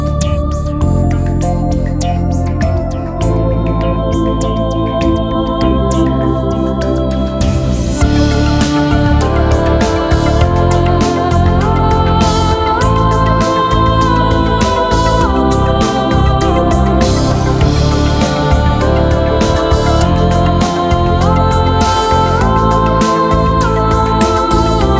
Звучание flac/wave (lossless)